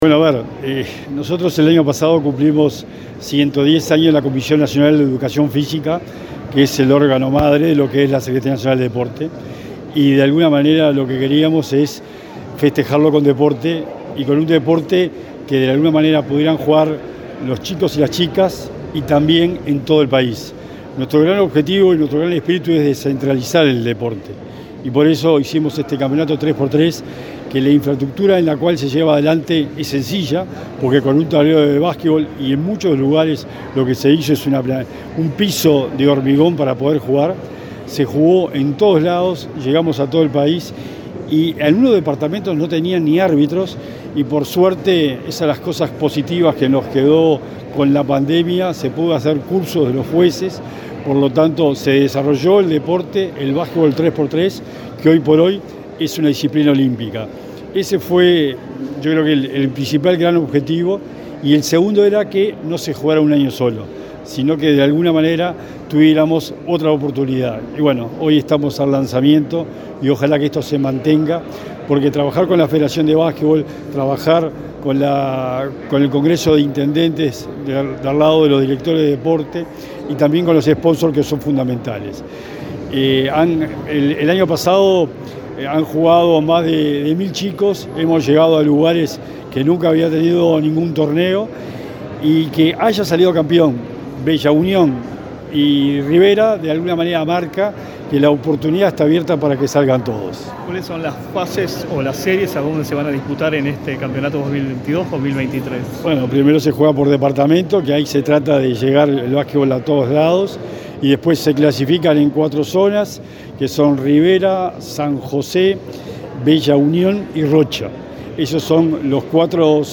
Entrevista al secretario nacional del Deporte, Sebastián Bauzá
El secretario nacional del Deporte, Sebastián Bauzá, dialogó con Comunicación Presidencial luego de presentar en Casa INJU el segundo Torneo Nacional